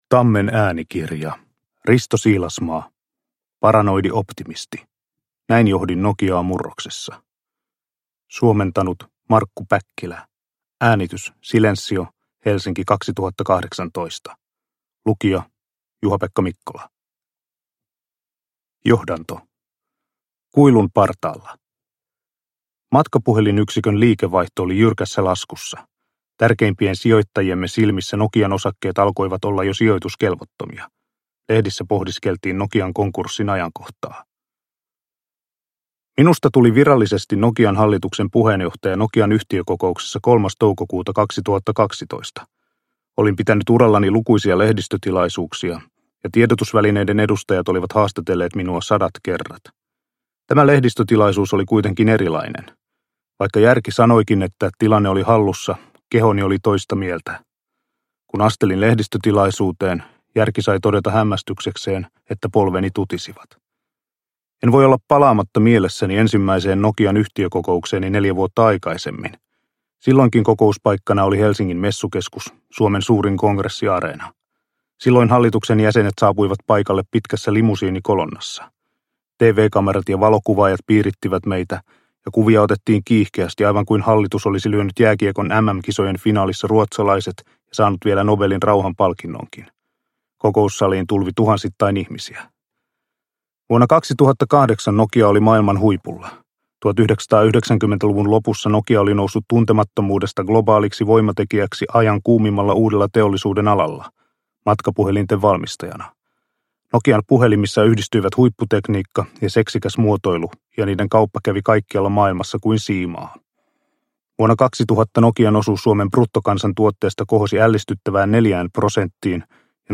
Paranoidi optimisti – Ljudbok – Laddas ner